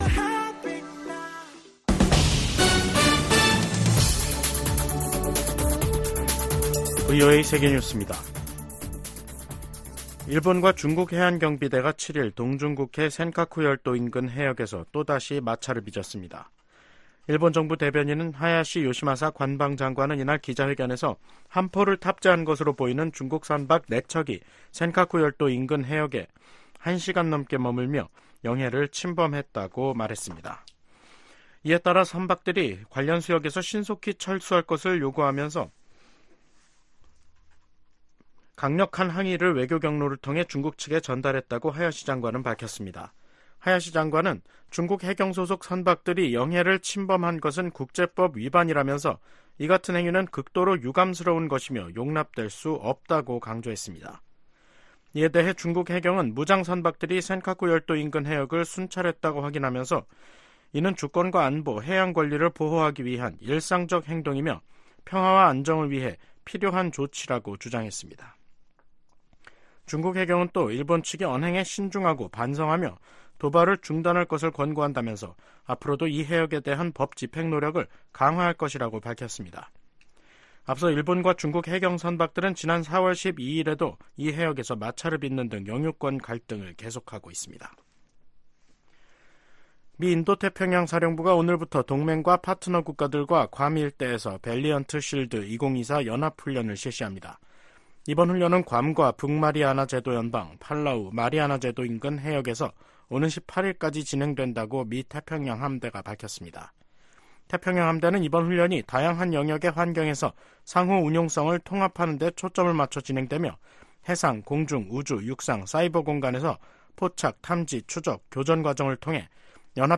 VOA 한국어 간판 뉴스 프로그램 '뉴스 투데이', 2024년 6월 7일 3부 방송입니다. 미국, 한국, 일본 3국의 협력은 인도태평양의 안보 구조와 정치 구조를 위한 ‘근본적인 체제’라고 미 국가안보부보좌관이 평가했습니다. 블라디미르 푸틴 러시아 대통령은 최근 세계 주요 뉴스통신사들과의 인터뷰에서 한국이 우크라이나에 무기를 공급하지 않고 있다고 이례적으로 감사 표시를 했습니다.